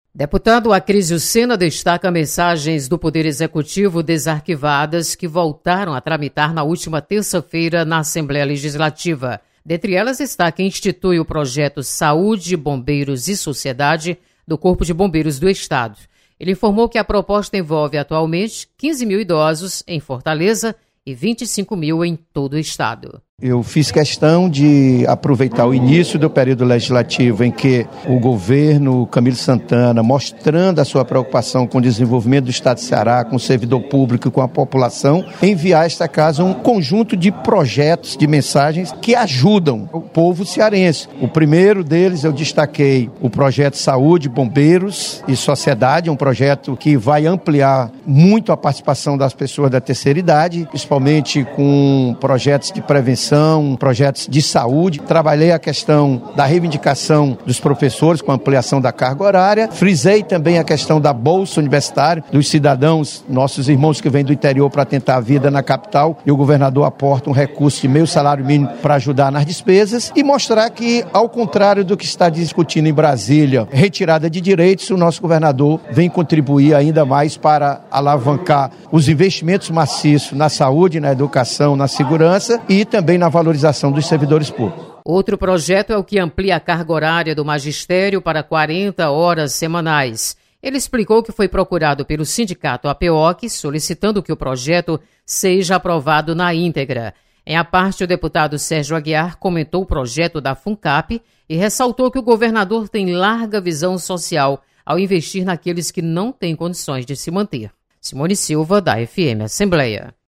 Mensagens do Governo do Estado iniciam tramitação na Assembleia. Repórter